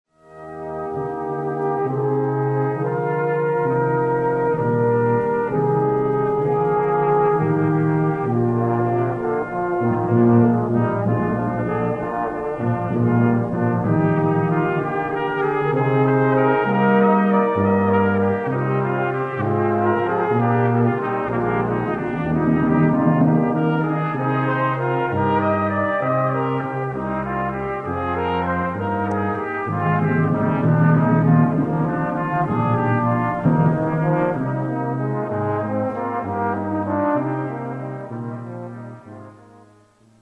Seaside Brass - Classical Repertoire - mp3's: